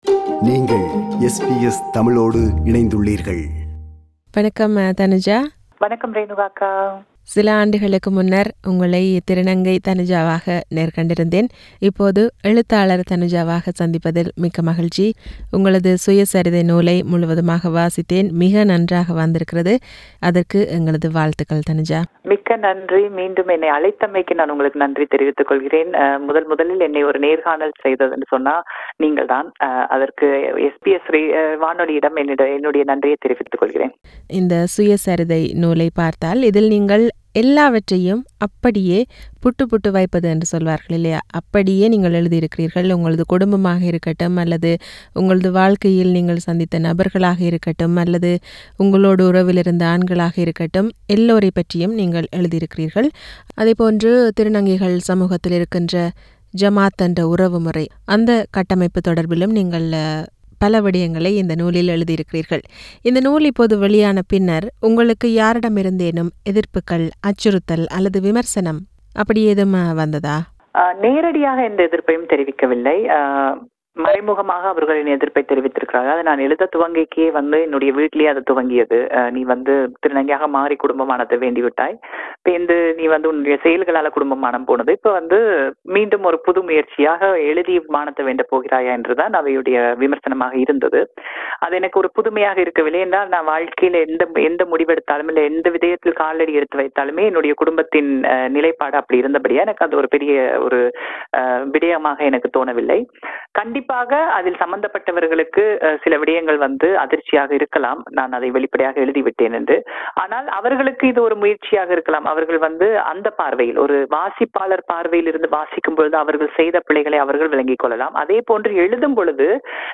This is an Interview